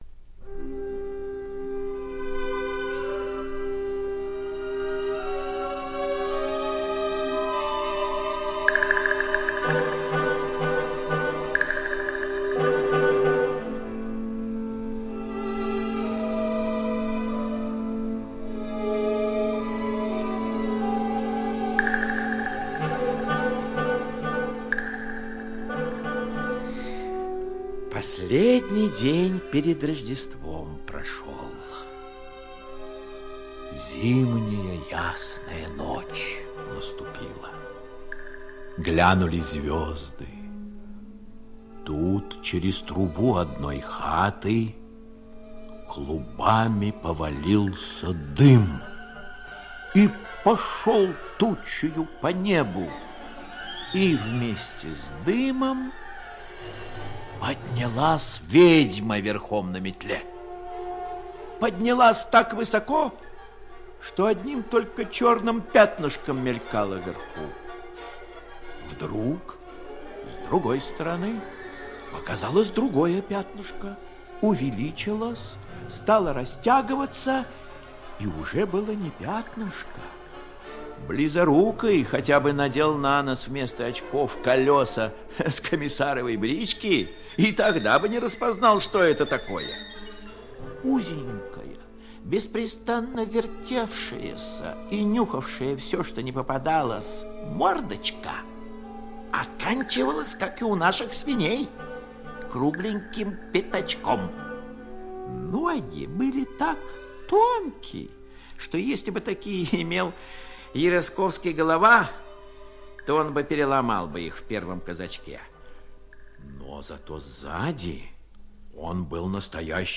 Ночь перед Рождеством - аудиосказка Гоголя Н.В. История о том, как в селе Диканька происходят мистические и смешные события...